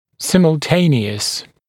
[ˌsɪml’teɪnɪəs][ˌсимл’тэйниэс]одновременный, синхронный